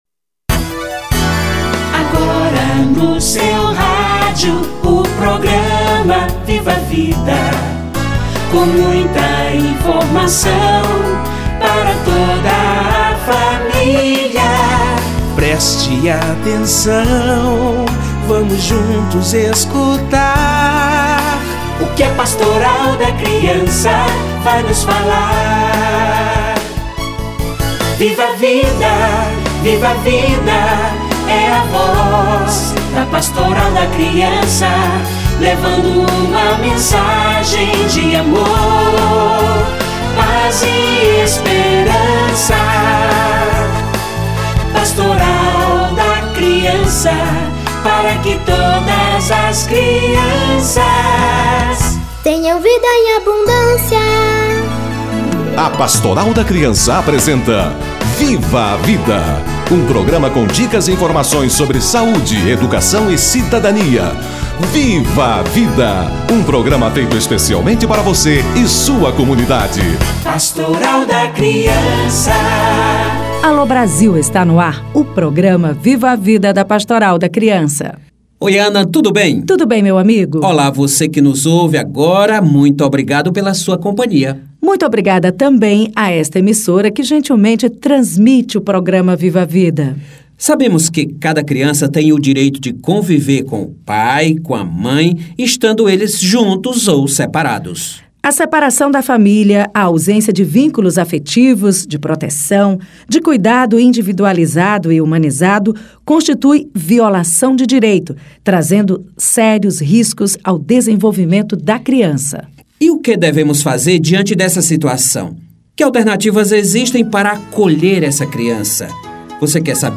Políticas públicas para a infância - Entrevista